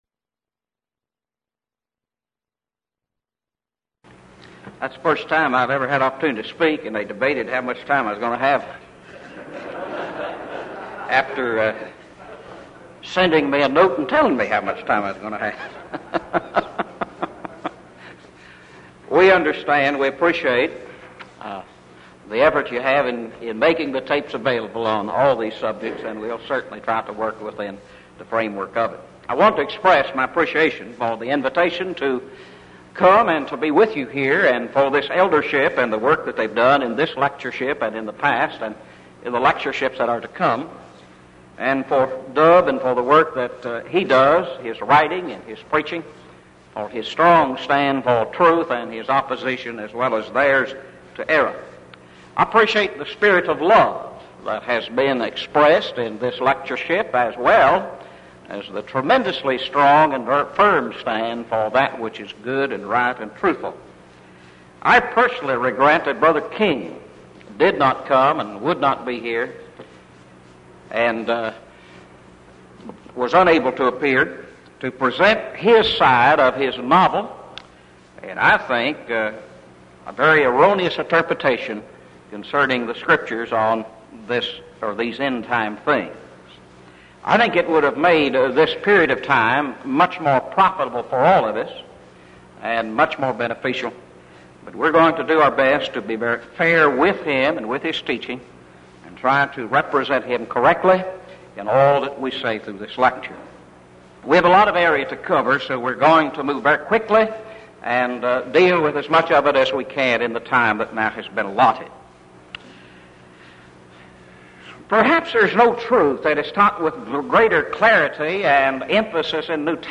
Title: DISCUSSION FORUM: Revelation 20:11-15; Is the Second Coming of Christ, Ushering in the Great Day of Judgment, Yet in the Future, or did It Occur in A.D. 70 with the Destruction of Jerusalem?